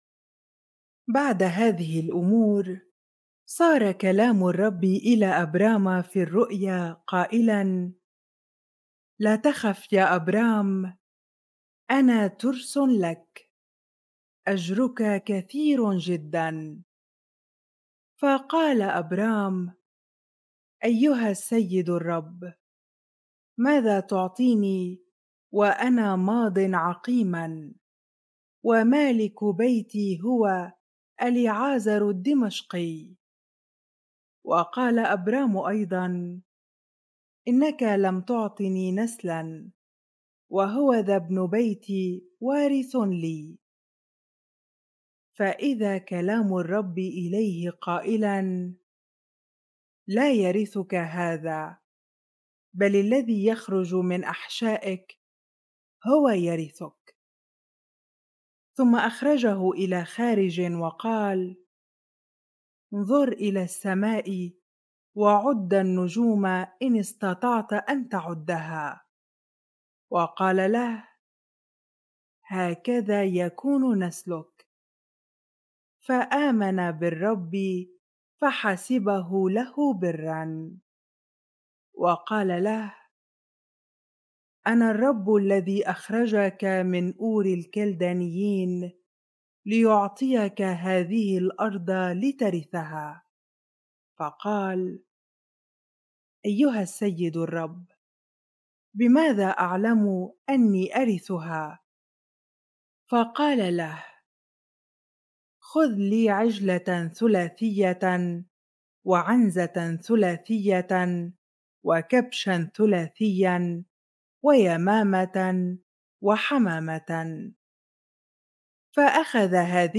bible-reading-genesis 15 ar